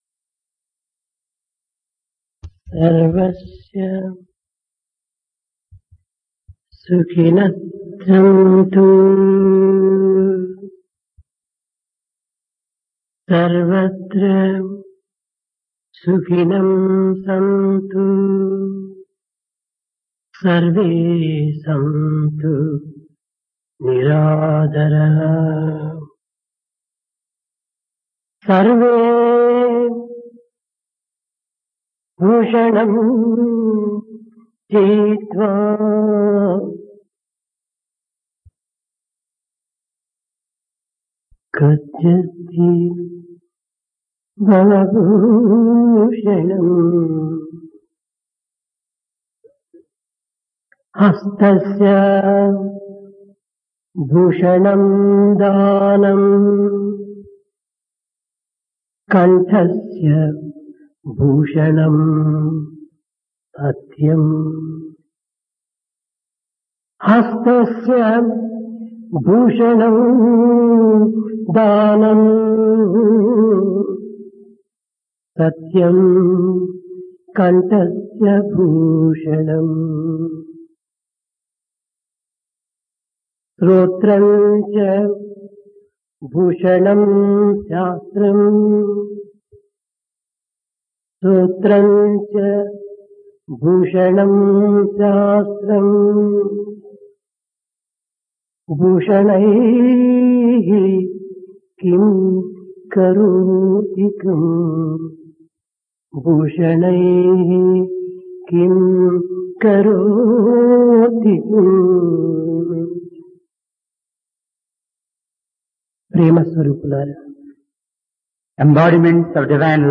Discourse
Place Prasanthi Nilayam Occasion Ganesh Chaturthi